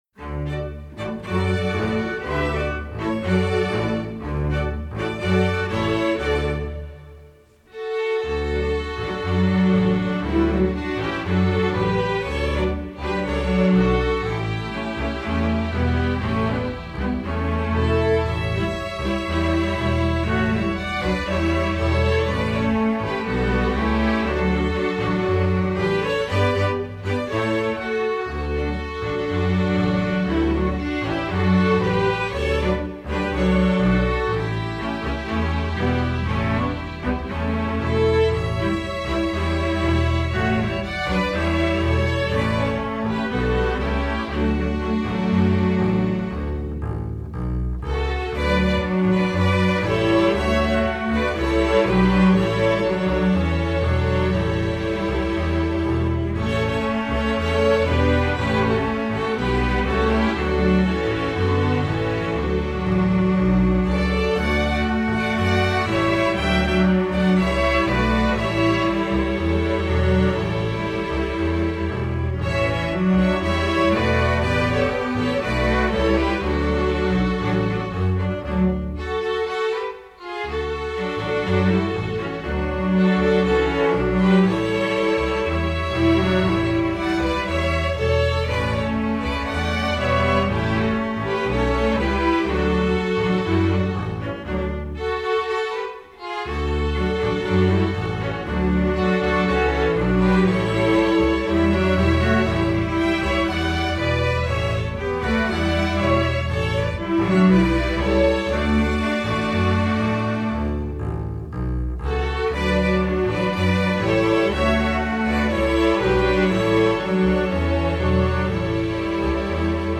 Voicing: Strg Orch